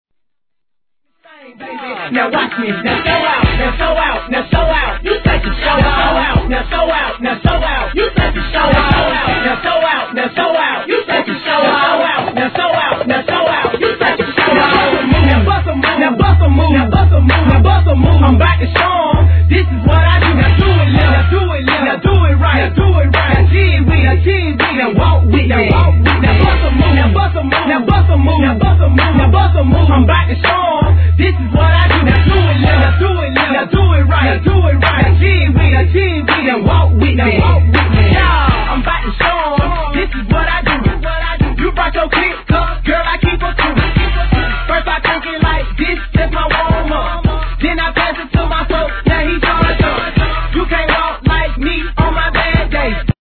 1. HIP HOP/R&B
(BPM 81)